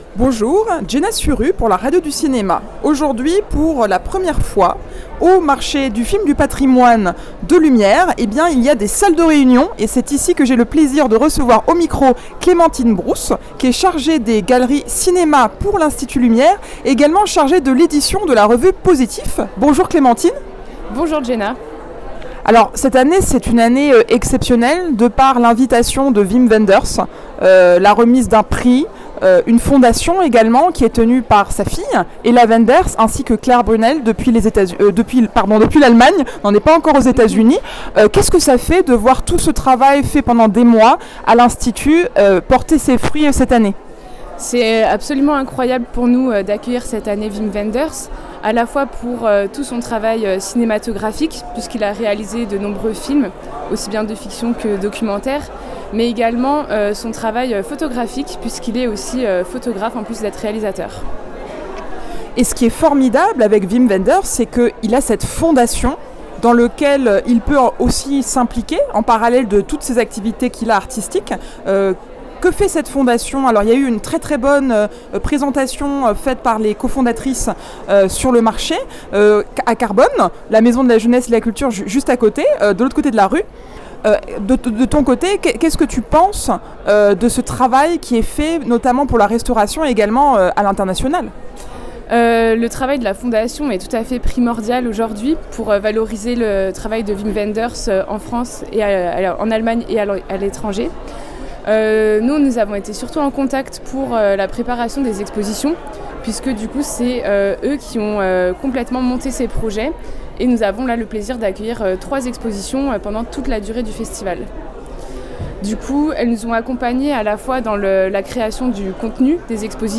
La 11e édition du Marché International du Film Classique s'est tenu pendant le festival Lumière.